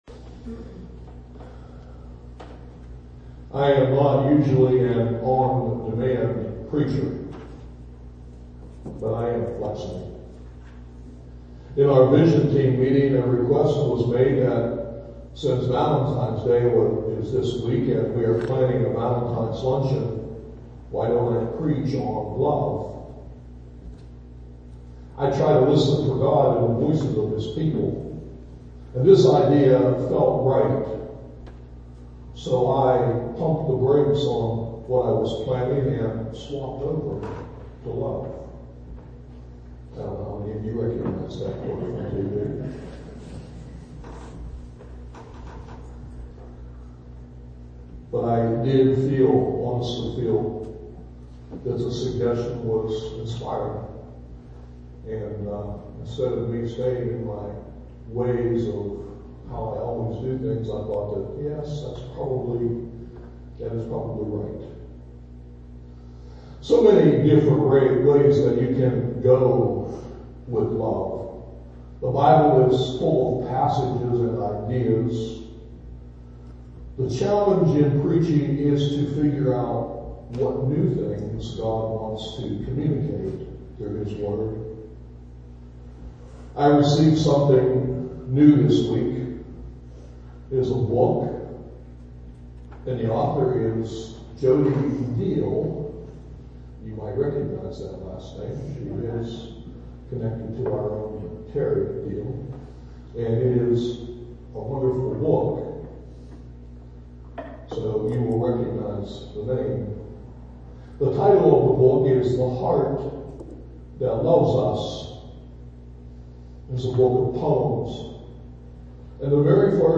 MORNING MESSAGE TEXT: 1 Corinthians 13: 1-13